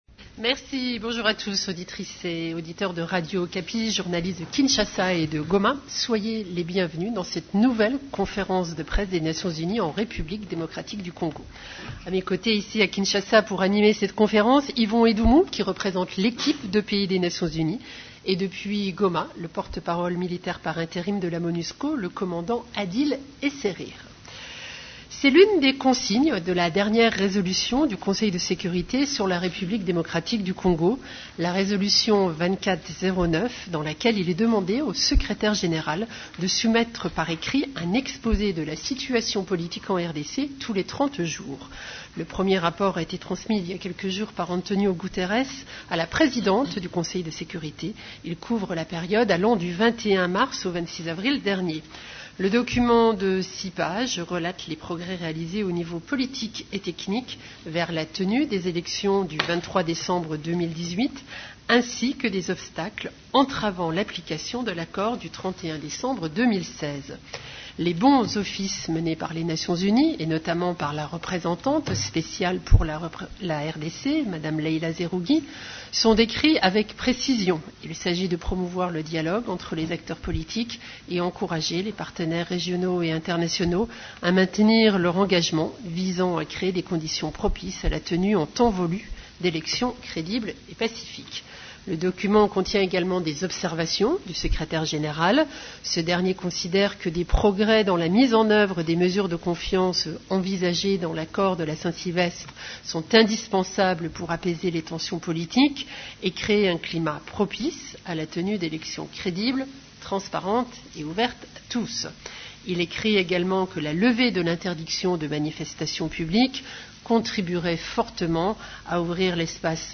Conférence de presse du mercredi 9 mai 2018
La conférence de presse hebdomadaire de l’ONU du mercredi 25 avril à Kinshasa et à Goma a porté sur les activités d’agences des Nations unies en RDC.